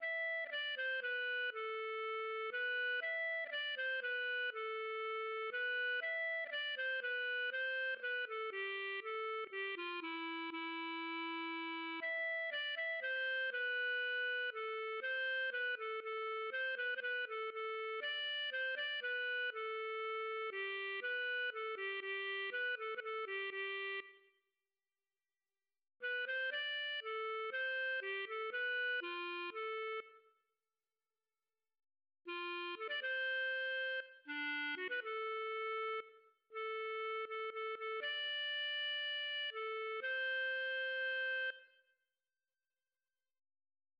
Ein Minnelied, gefunden auf Burg Luringen, Autor unbekannt: